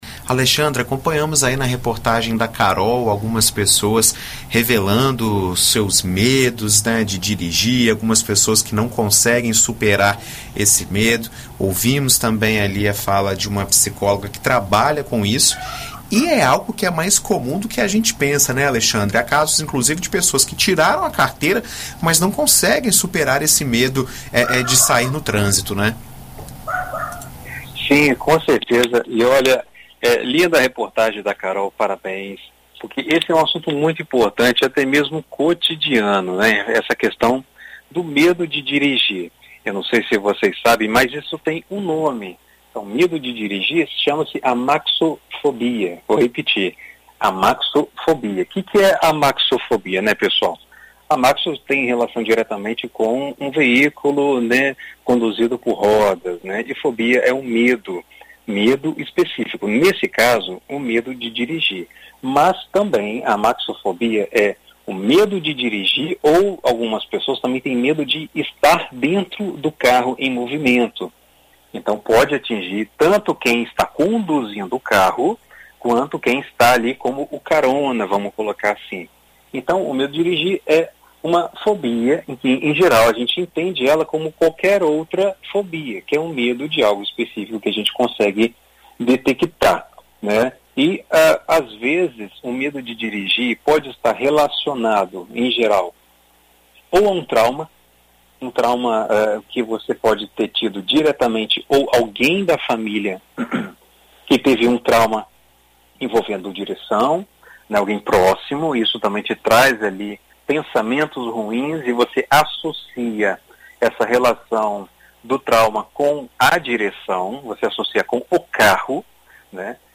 Psicólogo explica como tratar o medo de dirigir